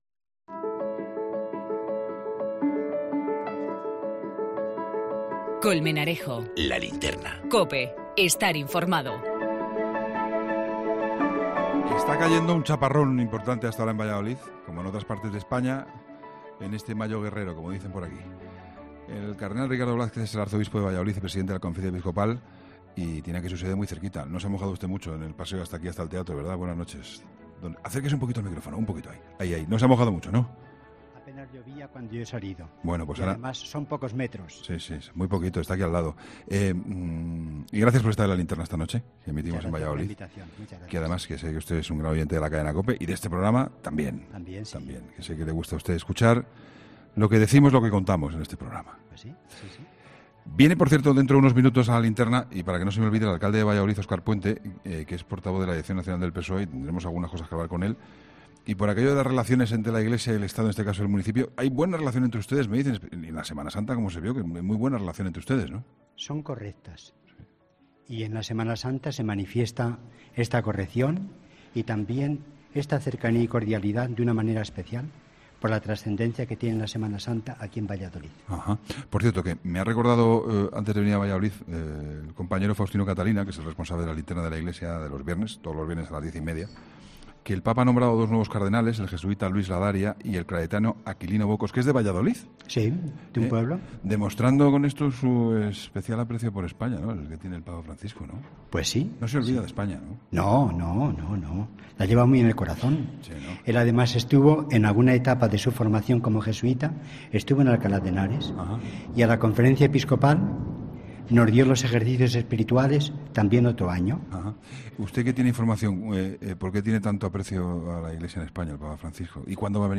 El cardenal arzobispo de Valladolid y presidente de la Conferencia Episcopal, Monseñor Ricardo Blázquez, ha participado en la emisión de 'La Linterna'...
'La Linterna' se ha emitido este jueves desde Valladolid.